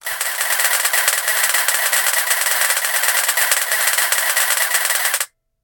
宣传媒体照相机抓拍音效铃声二维码下载
这是宣传媒体照相机抓拍音效手机铃声。